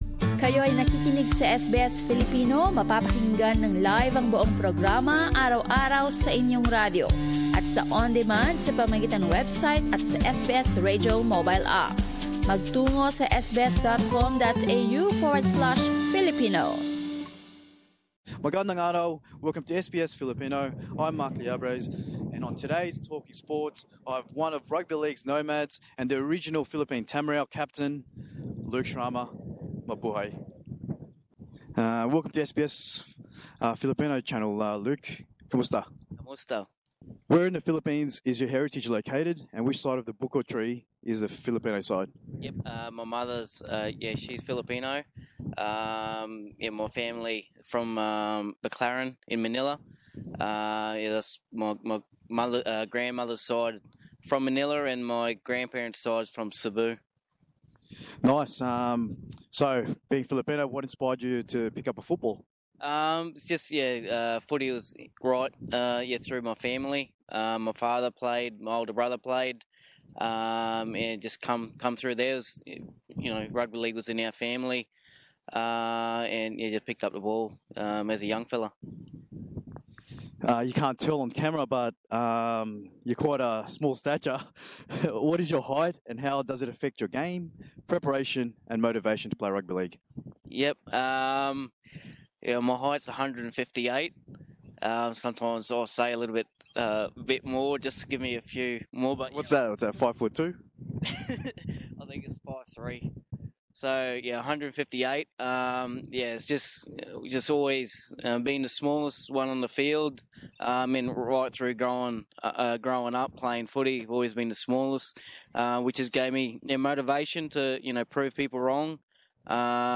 kinakapanayam